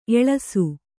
♪ eḷasu